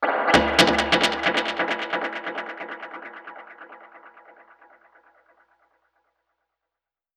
Index of /musicradar/dub-percussion-samples/134bpm
DPFX_PercHit_B_134-07.wav